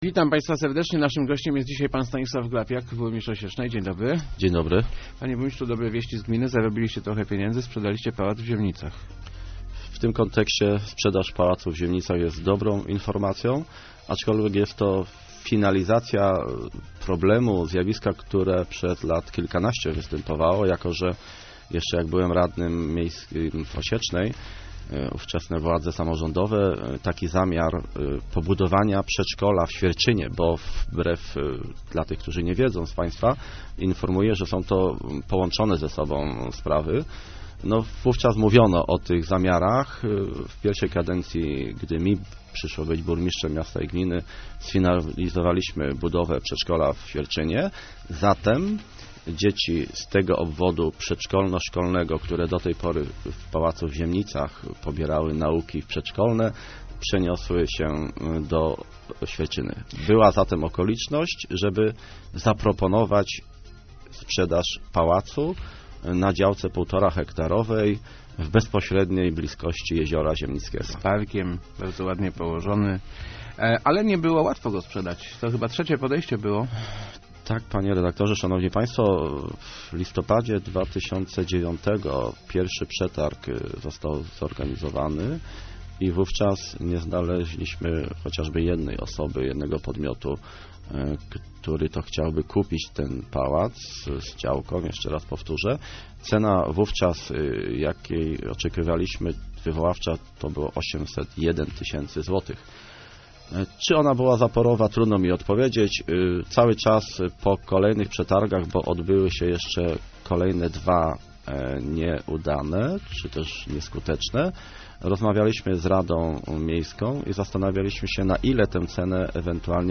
Udało nam się sprzedać pałac w Ziemnicach - mówił w Rozmowach Elki burmistrz Osiecznej Stanisław Glapiak. W przetargu osiągnięto zakładaną cenę prawie 700 tysięcy złotych za nieruchomość wraz z działką.